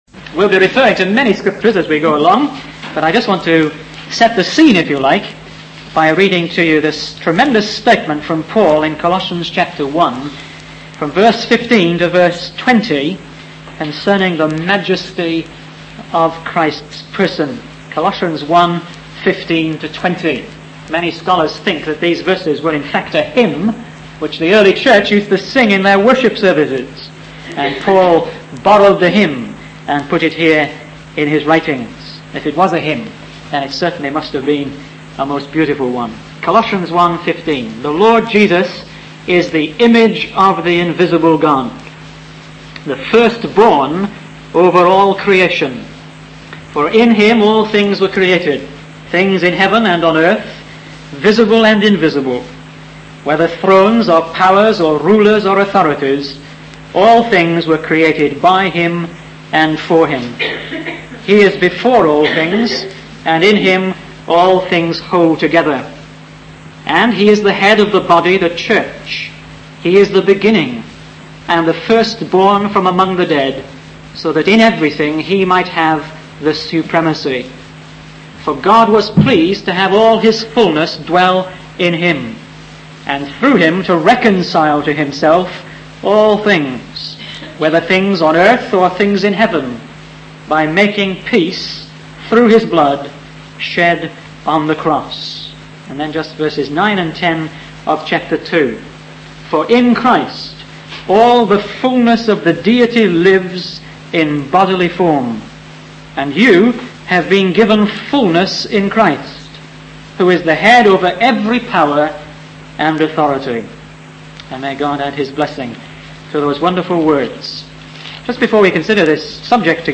In this sermon, the speaker discusses the impact of Jesus' life and teachings on humanity. He emphasizes that Jesus' sinlessness is a remarkable aspect of his character, as he challenges others to find any fault in him. The speaker also mentions the enduring influence of Jesus, despite his humble background and short public ministry.